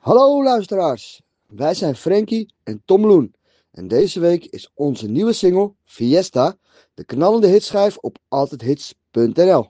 volkszanger
urban/pop sound